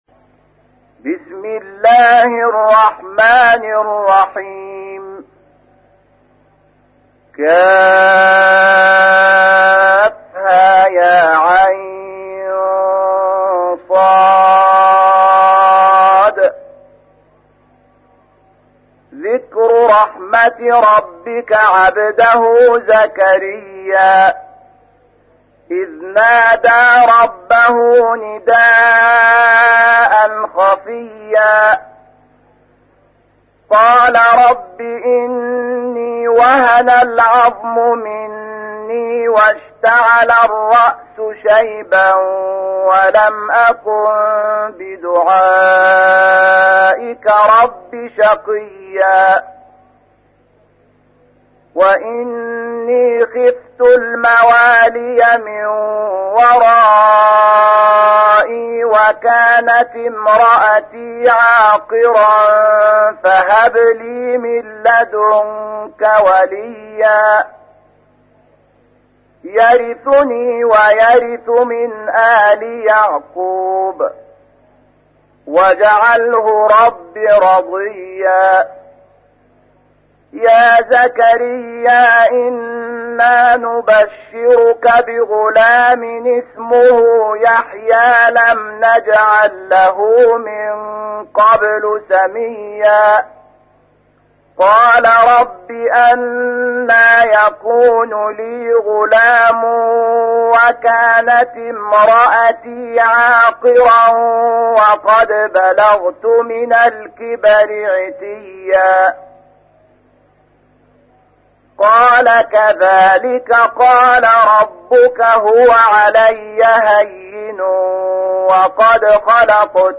تحميل : 19. سورة مريم / القارئ شحات محمد انور / القرآن الكريم / موقع يا حسين